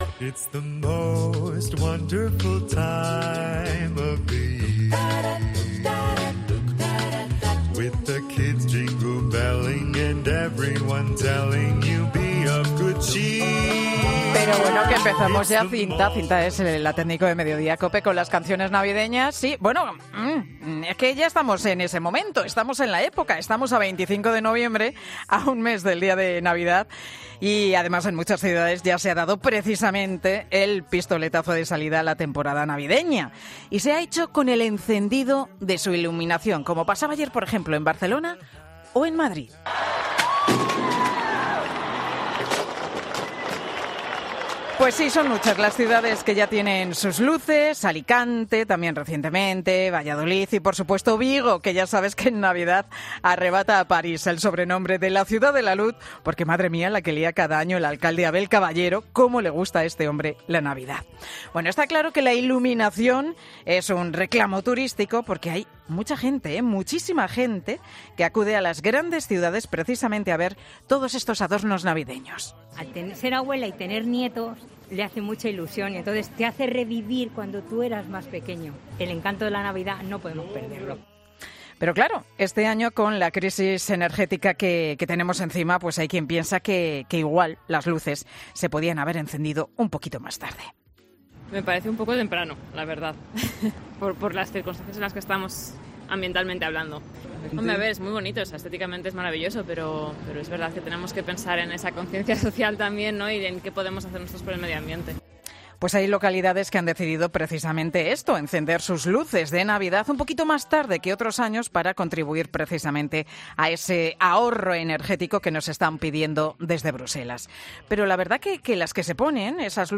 'Mediodía COPE' ha analizado el encendido de luces de Navidad y ha hablado con el alcalde de Ausejo, pueblo que se hizo viral por su iluminación del años pasado